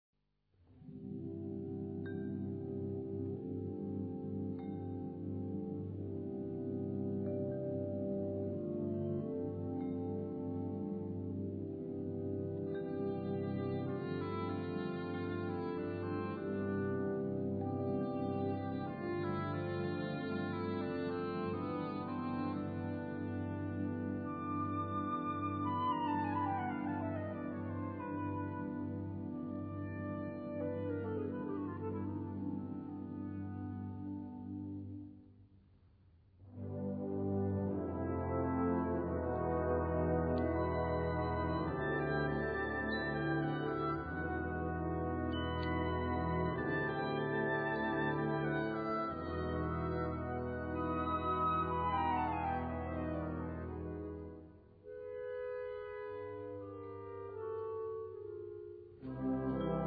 Gattung: Sinfonische Skizze
Besetzung: Blasorchester